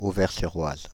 Auvers-sur-Oise (French pronunciation: [ovɛʁ syʁ waz]
Fr-Auvers-sur-Oise.ogg.mp3